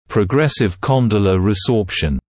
[prə’gresɪv ‘kɔndələ rɪˈzɔːpʃn][прэ’грэсив ‘кондэлэ риˈзоːпшн]прогрессирующая мыщелковая резорбция